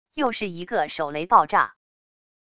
又是一个手雷爆炸wav音频